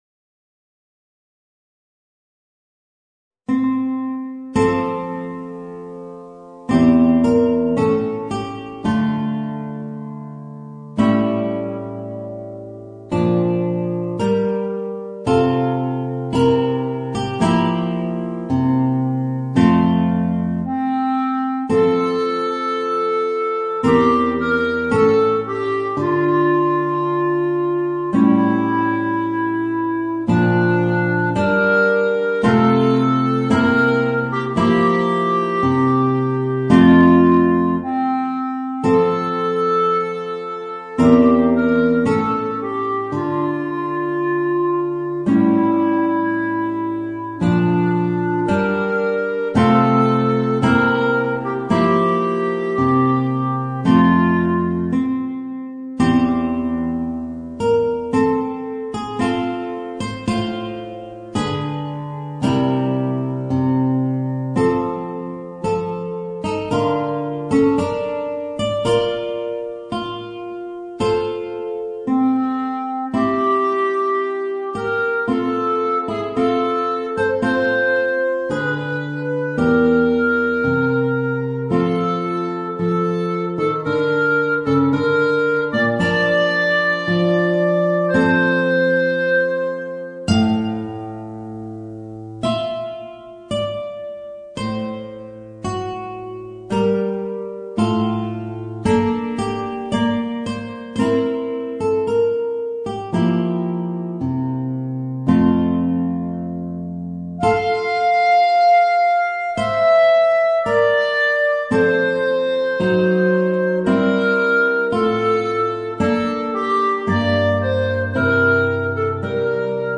Voicing: Guitar and Clarinet